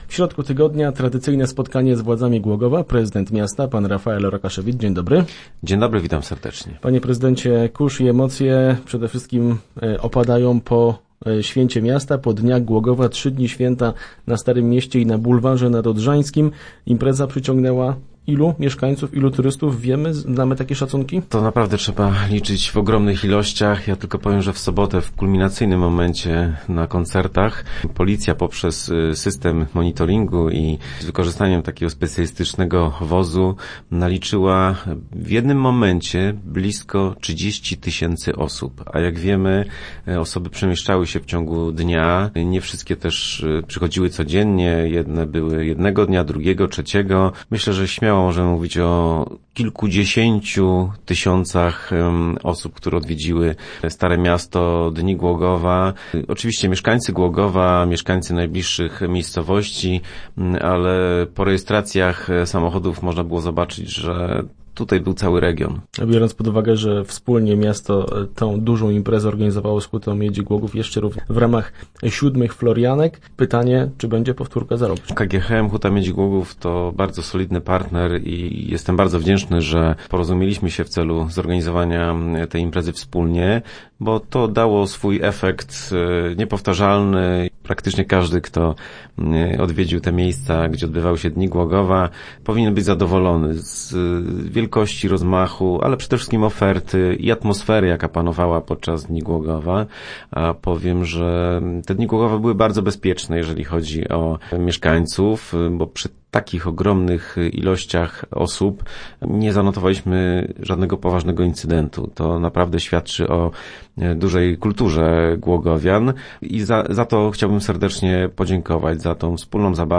Podsumowanie Dni Głogowa w środowych Rozmowach Elki. W studiu gościliśmy prezydenta miasta Rafaela Rokaszewicza.